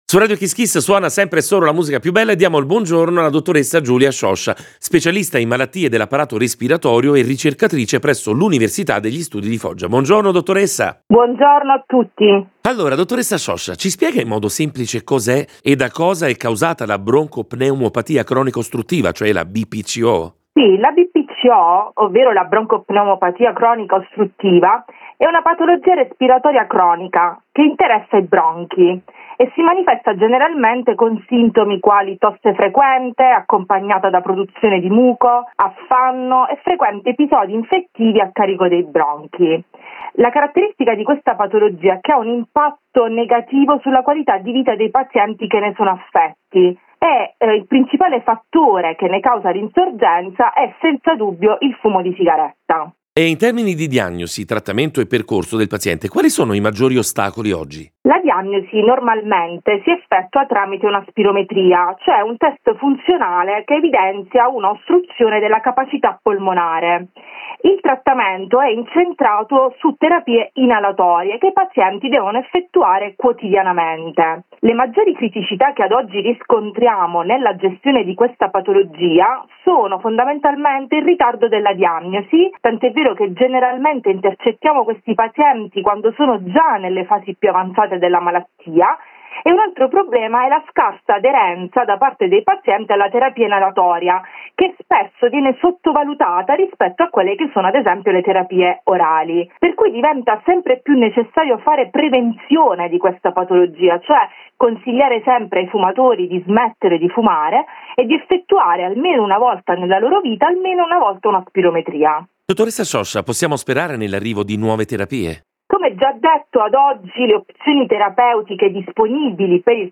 Intervenuta ai microfoni di Radio Kiss Kiss